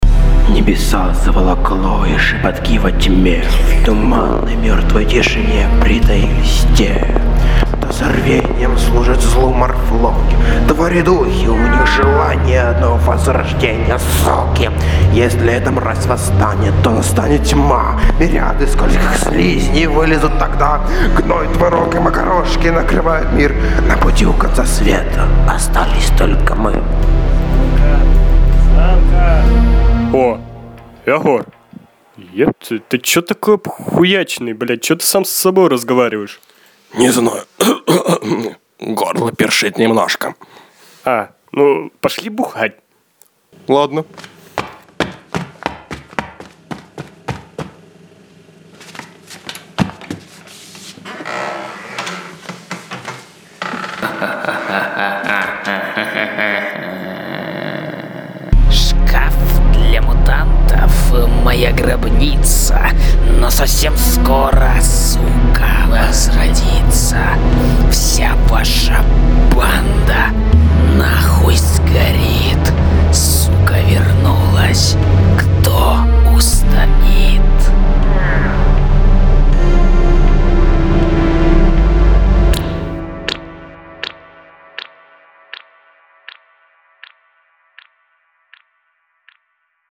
• Categoria: Suoni dal pozzo